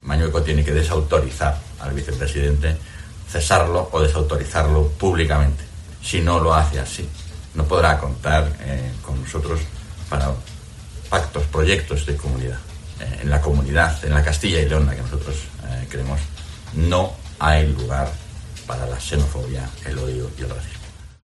Francisco Igea (Cs) pide el cese del vicepresidente de la Junta de Castilla y León